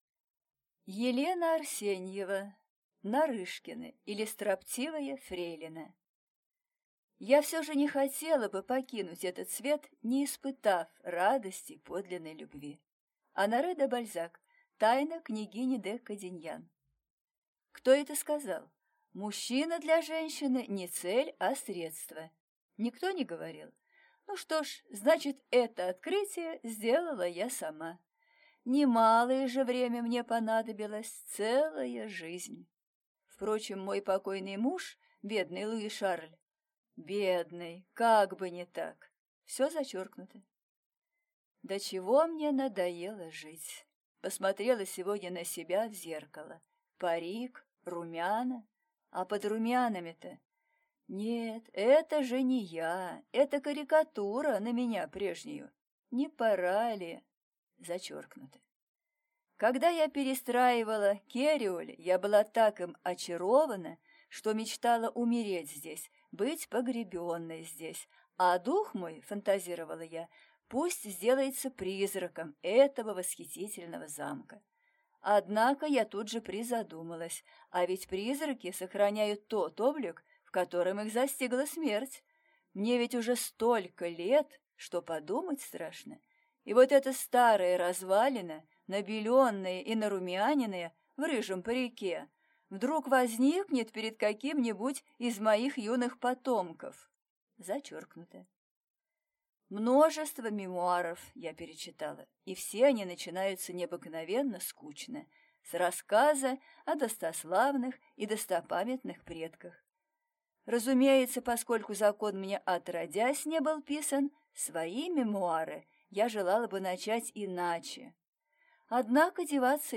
Аудиокнига Нарышкины, или Строптивая фрейлина | Библиотека аудиокниг